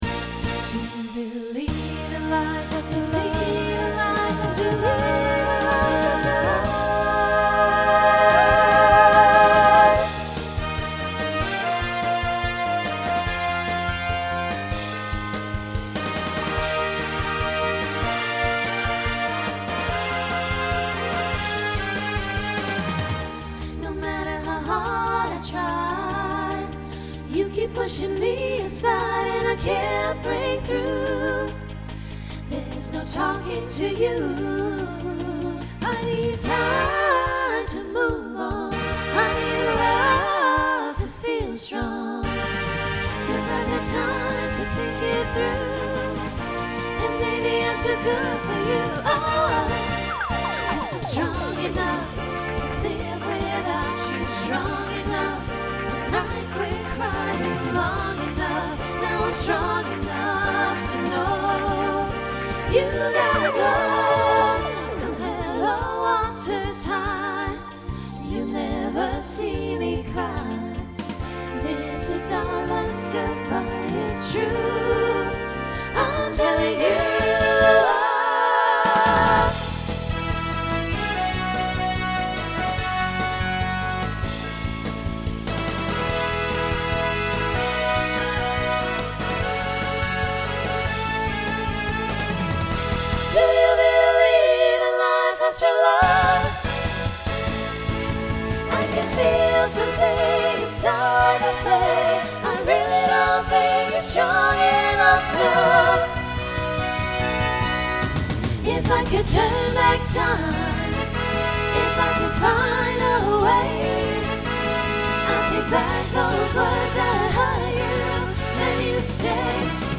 Voicing SSA Instrumental combo Genre Pop/Dance